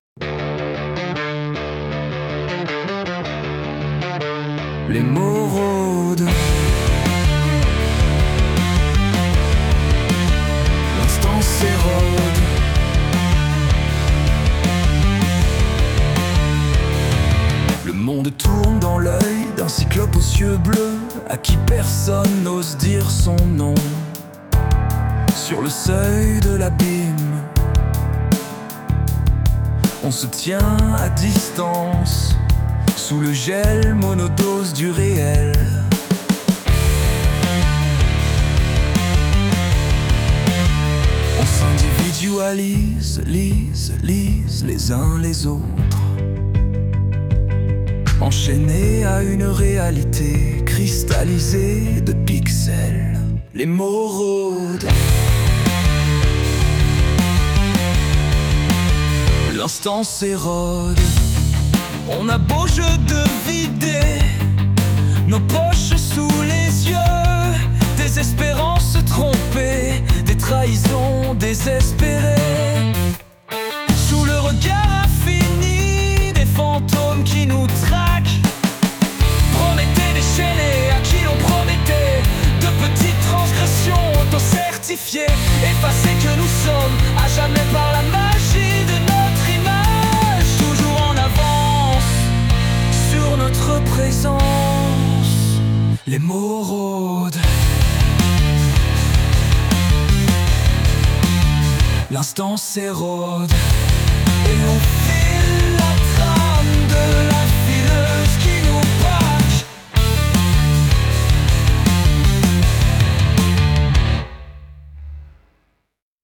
Les-mots-rodent-dark-rock-beats-post-punk.mp3